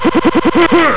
E.Honda-100-hand-slap.wav